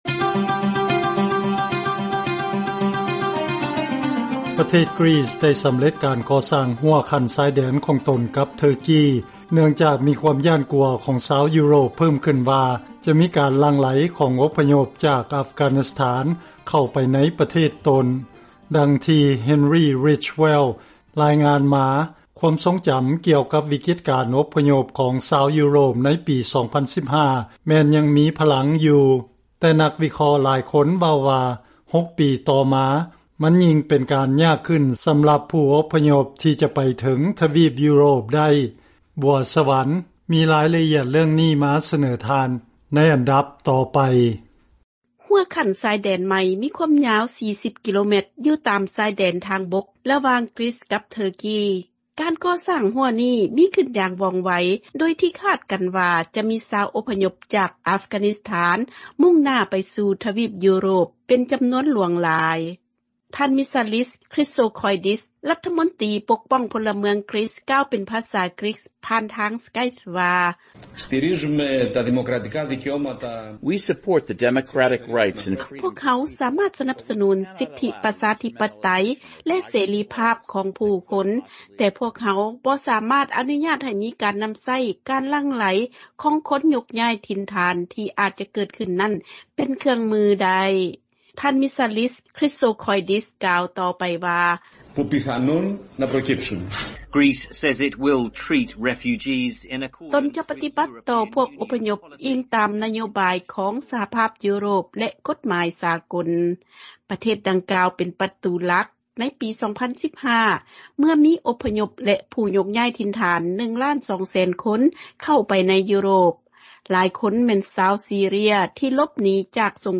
ເຊີນຟັງລາຍງານເລື້ອງການສ້າງຮົ້ວຊາຍແດນເພື່ອສະກັດກັ້ນການຫລັ່ງໄຫລຂອງອົບພະຍົບເຂົ້າໄປປະເທດຢູໂຣບ